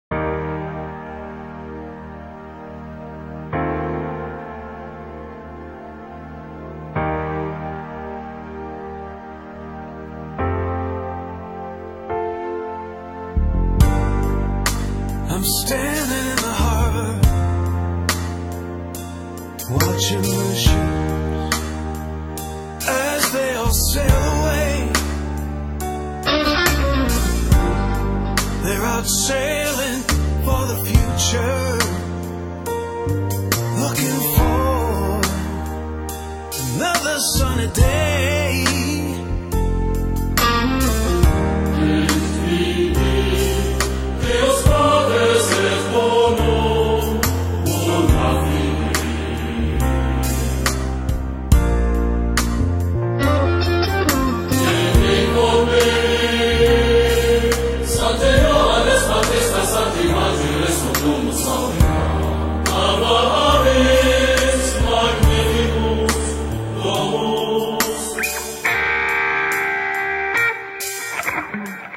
использованы оперные партии